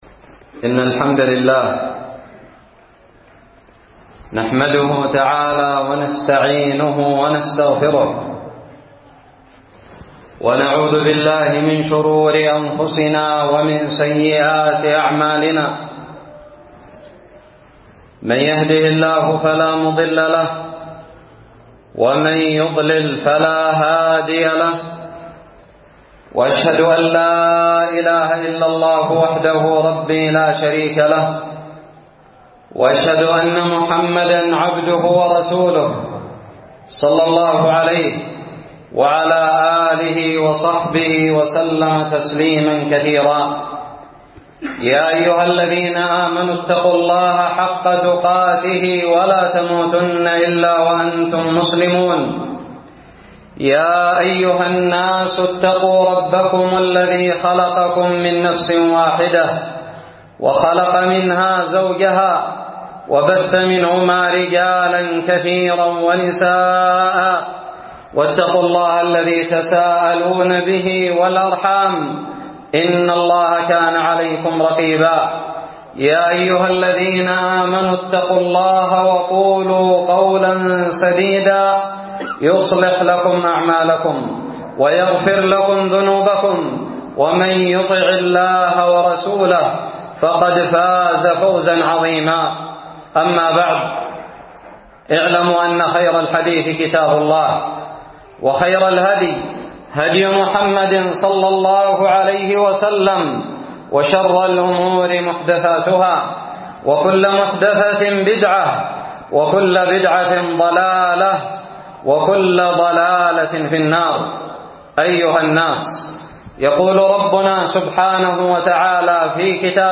خطب الجمعة
ألقيت بدار الحديث السلفية للعلوم الشرعية بالضالع في 3 جمادى الآخرة 1438هــ